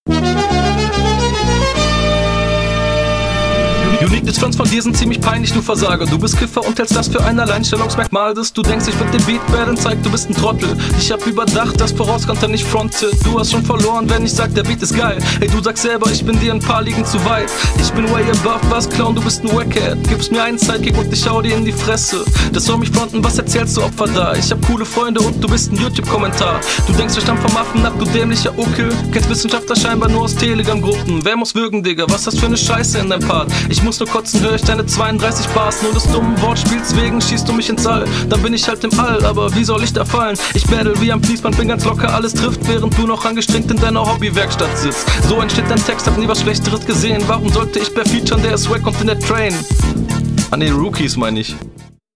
Der Stimmeinsatz ist ähnlich gechillt, aber klingt weniger gesprochen.